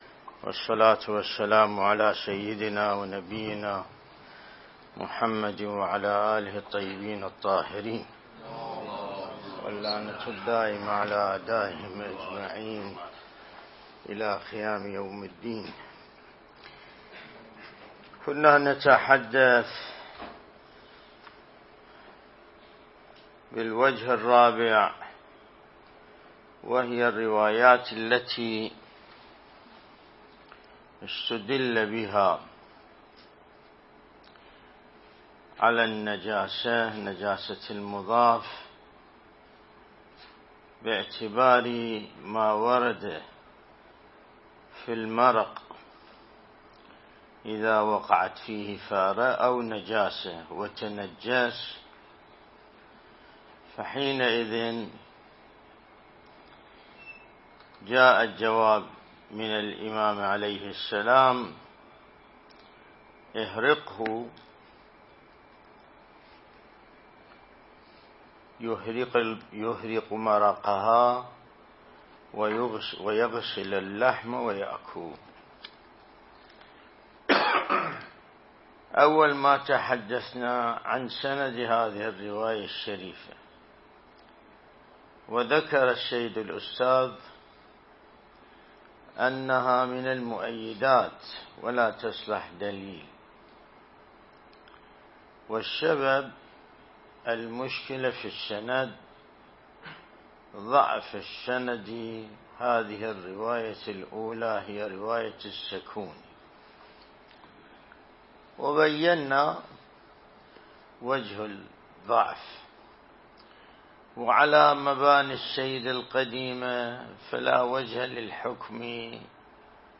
الدرس الاستدلالي شرح بحث الطهارة من كتاب العروة الوثقى لسماحة آية الله السيد ياسين الموسوي (دام ظله)